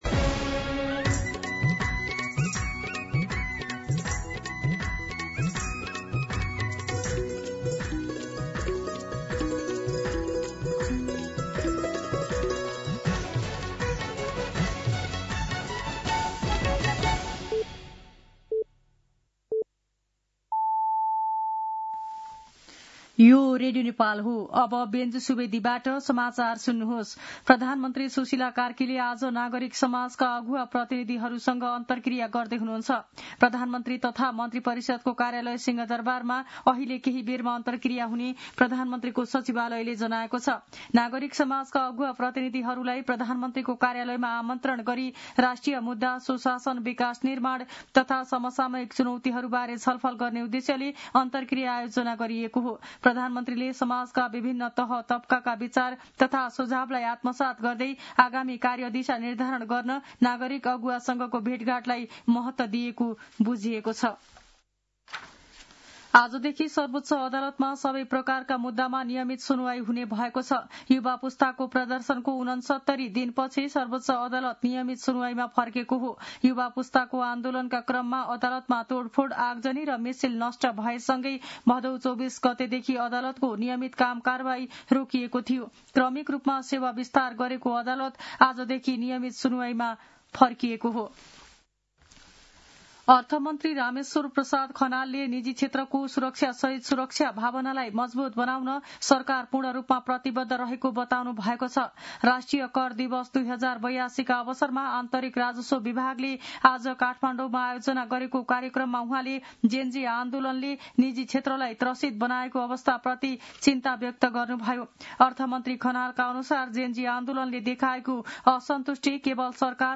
दिउँसो १ बजेको नेपाली समाचार : १ मंसिर , २०८२
1-pm-News-8-1.mp3